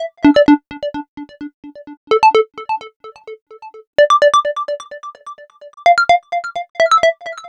Session 11 - Bubbley Arpeggiator.wav